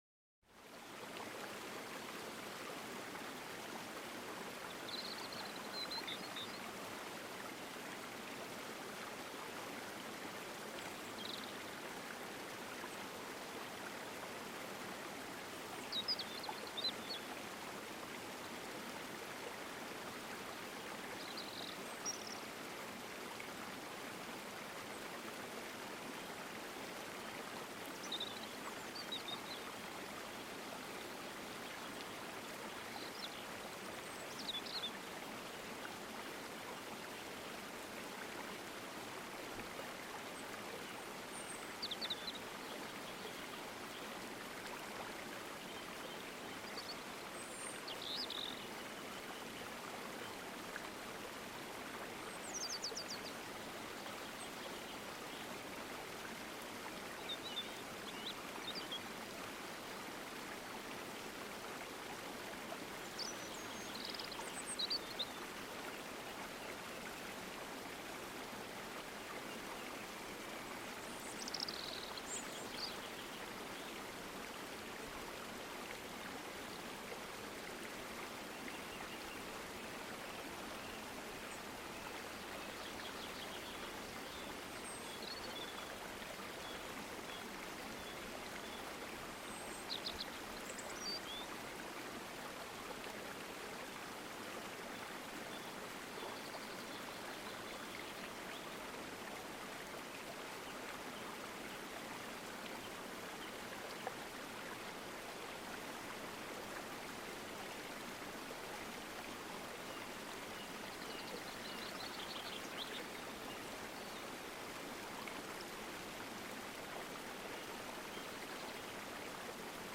SANFTE ENTSPANNUNG: Waldesruh-Harmonie vereint Vogelgesang + Wasser